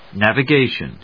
音節nav・i・ga・tion 発音記号・読み方
/n`ævəgéɪʃən(米国英語), ˈnævʌˈgeɪʃʌn(英国英語)/